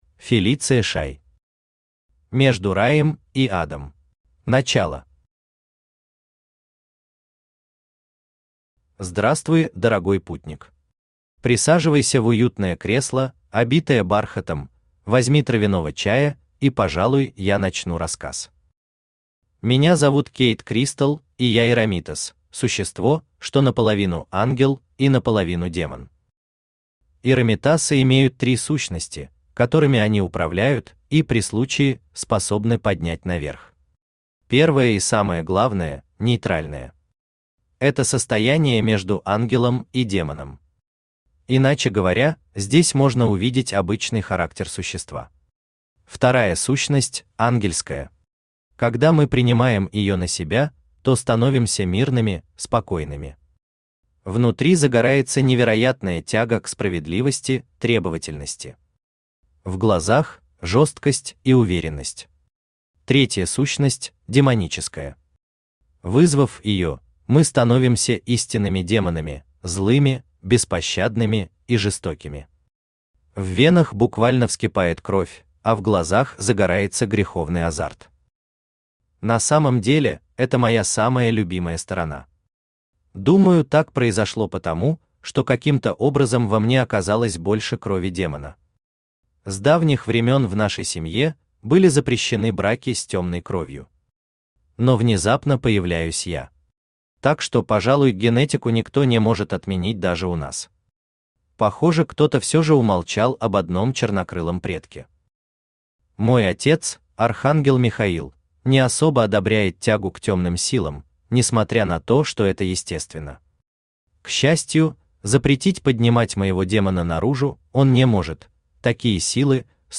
Аудиокнига Между Раем и Адом | Библиотека аудиокниг
Aудиокнига Между Раем и Адом Автор Фелиция Шай Читает аудиокнигу Авточтец ЛитРес.